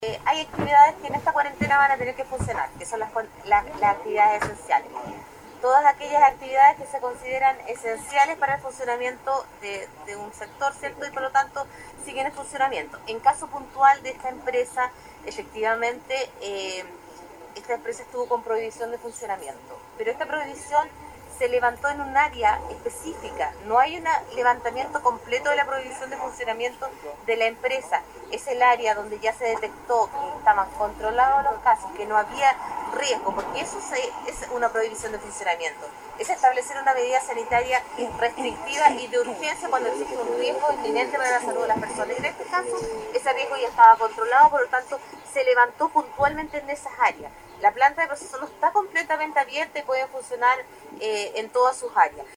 Marcela Cárdenas, seremi de salud (S), destacó la normativa que rige para esta medida de confinamiento y puntualmente se refirió a la situación de la empresa Salmones Antártica, la que pese a la prohibición de funcionamiento, mantiene personal laborando, generando intranquilidad en la comunidad.